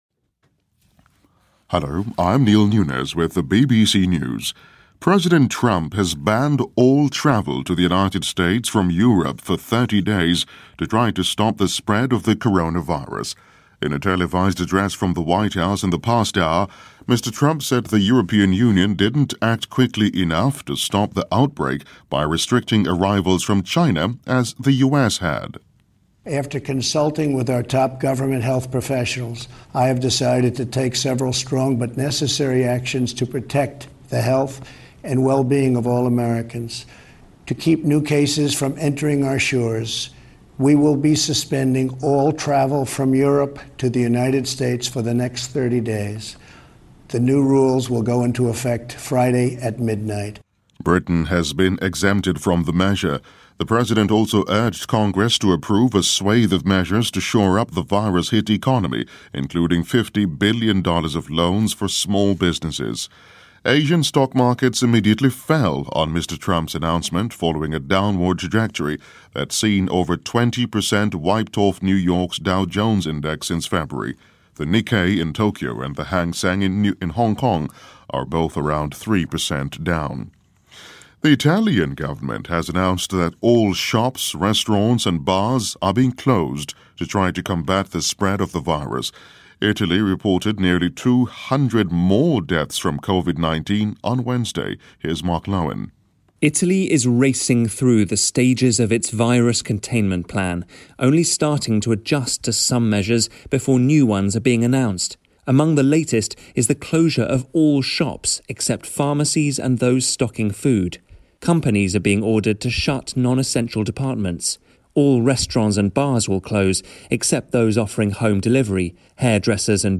News
英音听力讲解:特朗普宣布实施欧洲旅行禁令